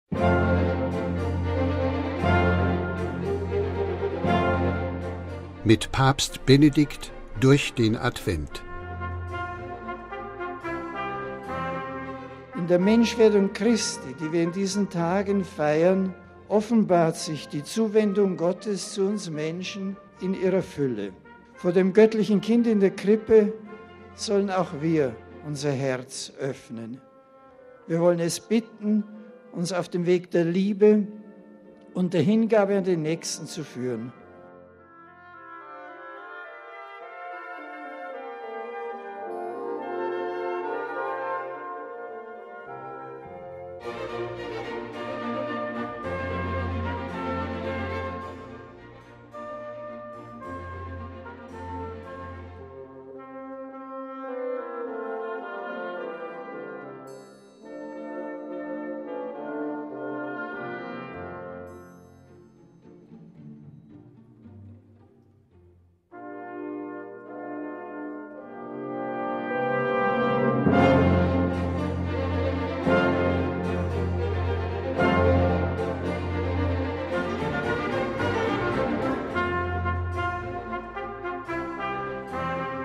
(Benedikt XVI.; aus der Generalaudienz vom 28.12.2005)